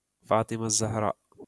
فاطمة الزهراء[fāṭimah azzahrāʾ] Информация о файле